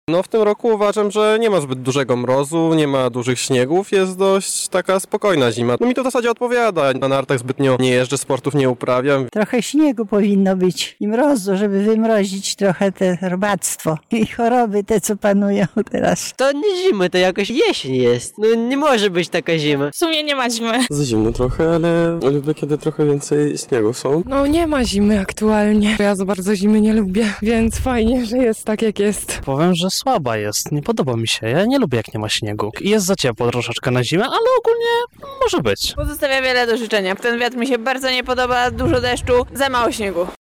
W związku z tym nasz reporter zapytał mieszkańców Lublina, co sądzą o takiej zimie jaką mamy: